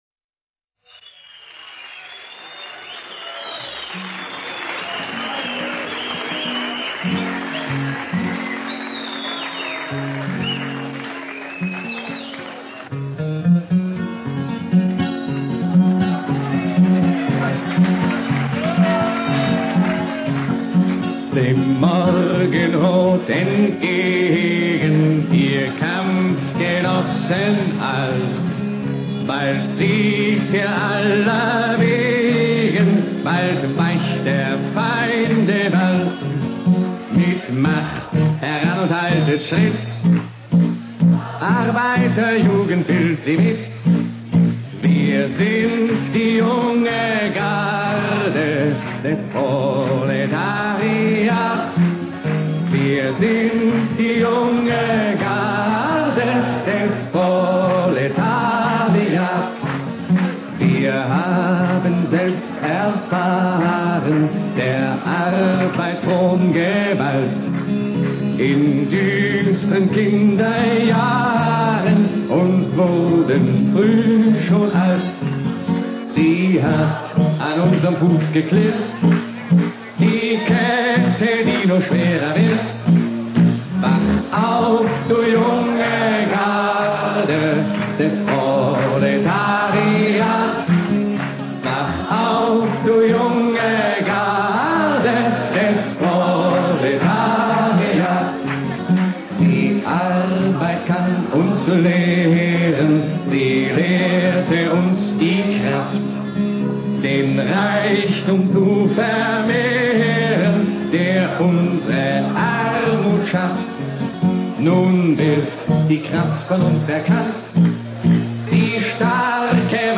Гимн немецкой социал-демократической молодежи начала ХХ века